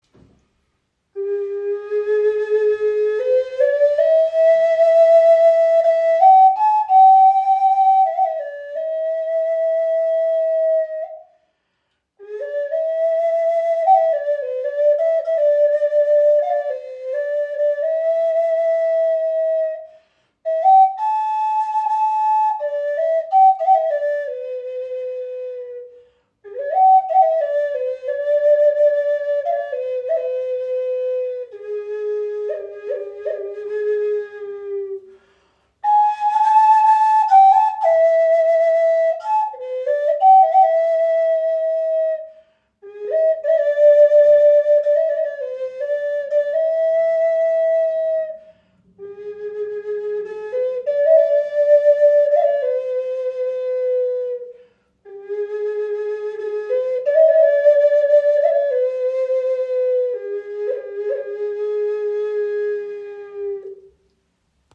Okarina aus einem Aststück | A4 in 432 Hz | Pentatonische Stimmung | ca. 23 cm
Klein, handlich, klangvoll – eine Okarina mit Seele
Handgefertigte 5 Loch Okarina aus Teakholz – pentatonische A4 Moll Stimmung in 432 Hz, warmer klarer Klang, jedes Stück ein Unikat.
Trotz ihrer handlichen Grösse erzeugt sie einen angenehm tiefen und warmen Klang – fast ebenbürtig zur nordamerikanischen Gebetsflöte.